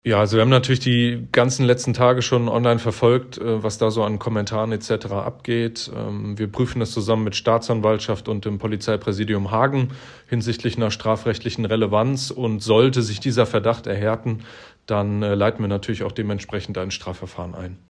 Man kann sich damit schnell strafbar machen. Polizeipressesprecher